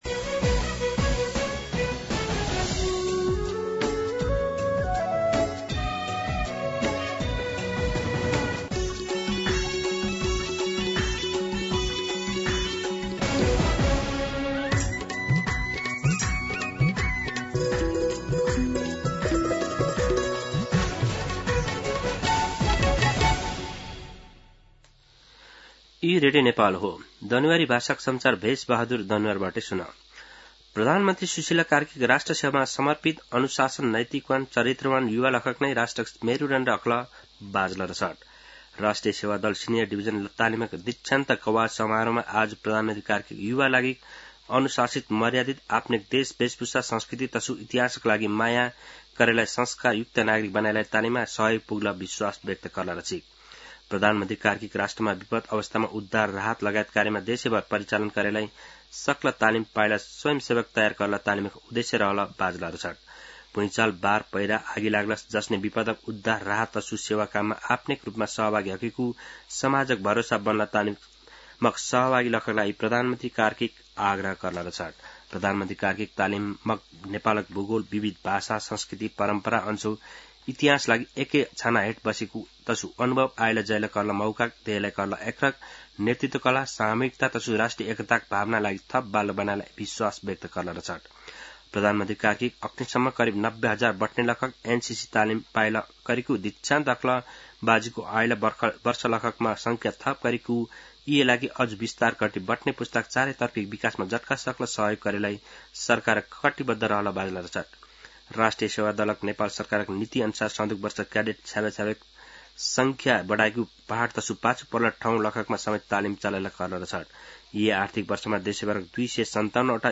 दनुवार भाषामा समाचार : २० माघ , २०८२
Danuwar-News-10-20.mp3